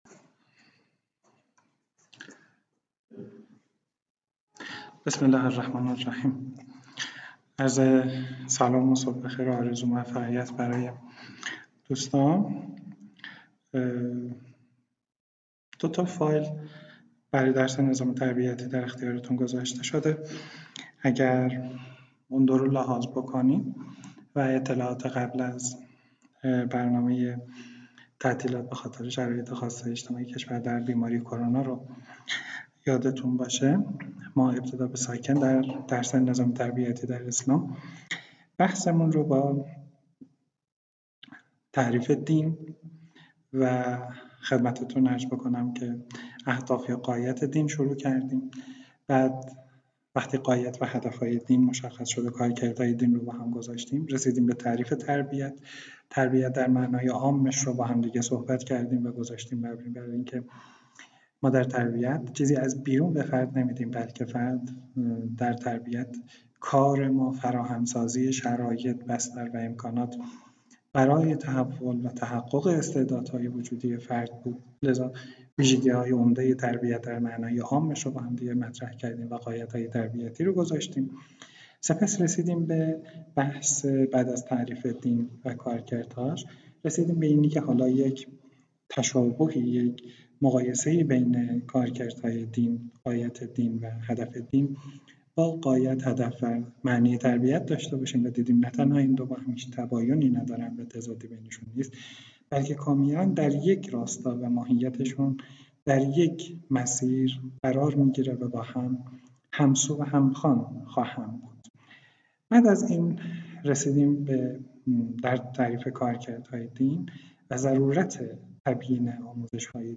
فایل صوتی بخش اول و دوم نظام تربیتی اسلام کلاس